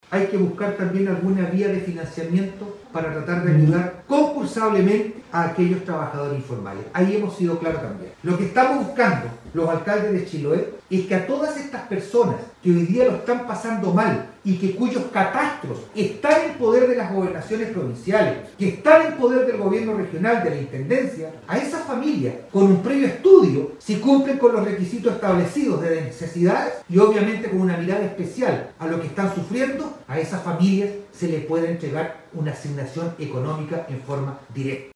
A través de una conferencia de prensa la Asociación de Municipalidades de Chiloé AMSUR, cuestionó la extensión de la cuarentenas en dicha provincia, calificándola como una medida injusta si se consideran las cifras actuales de contagios por Covid-19, sumándose a las críticas de otros alcaldes de la Región de Los Lagos a las autoridades del nivel central, por no cumplir con la palabra empeñada y poner fin al confinamientos después de 4 semanas. En la oportunidad, el alcalde de Castro, Juan Eduardo Vera, señaló que así como hay líneas de financiamiento para ayudar a los emprendedores y trabajadores formales, es necesario buscar ayuda directa para los trabajadores informales.